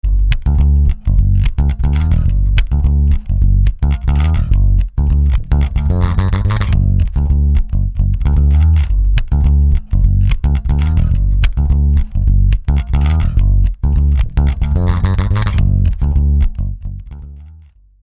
Same sound with 1/8 sampl. freq aliasing (5512.5 Hz) 00' 18" alias007